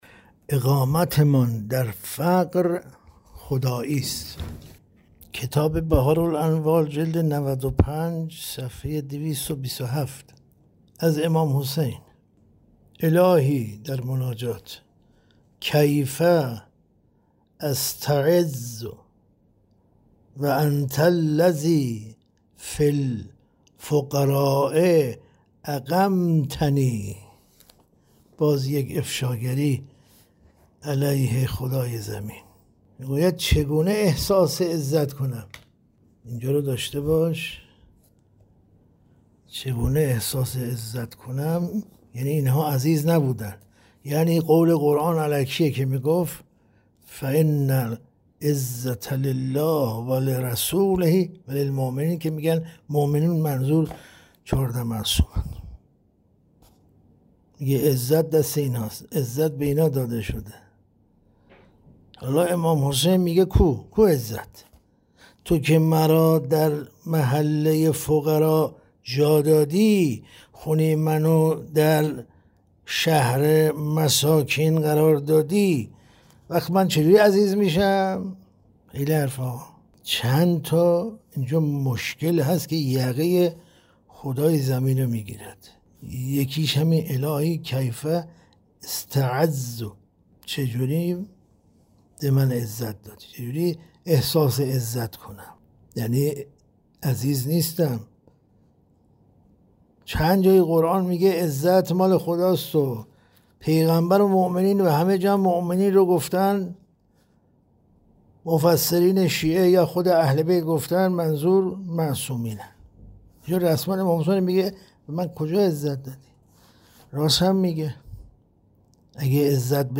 در این بخش، می‌توانید گزیده‌ای از تدریس‌های روزانه بروجردی، کاشف توحید بدون مرز، را مطالعه کرده و فایل صوتی آن را بشنوید.